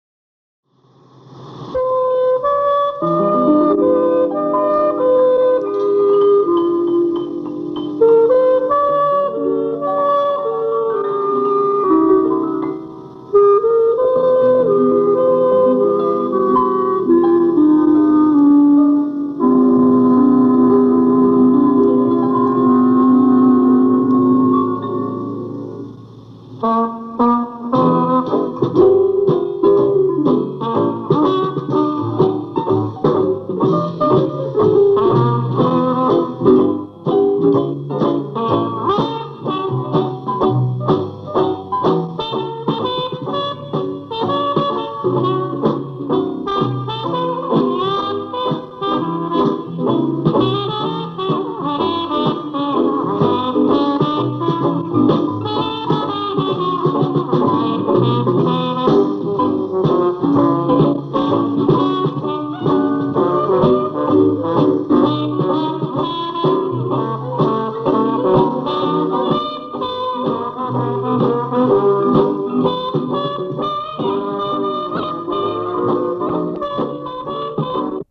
Диксиленд
запись из телетеатра на Каменном острове. 1968/1969.
труба
кларнет
тромбон
рояль
ударные
контрабас